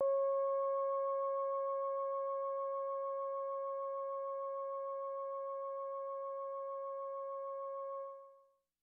Roland Jupiter 4 Mellow Organ " Roland Jupiter 4 Mellow Organ C6 ( Mellow Organ85127)
标签： CSharp6 MIDI音符-85 罗兰木星-4 合成器 单票据 多重采样
声道立体声